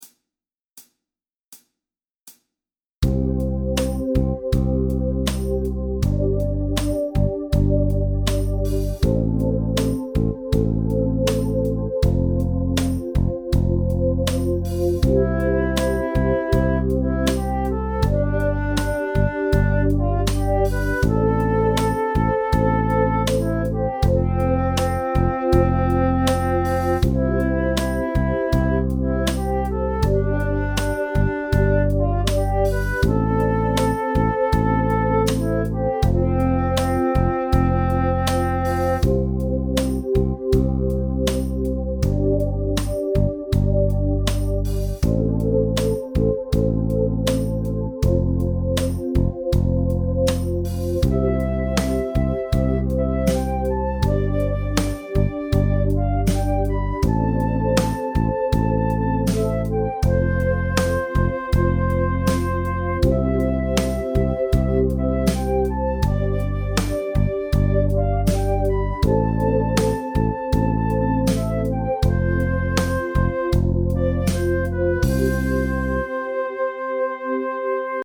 Piano / Teclado
Acompanhamento para os exercícios da apostila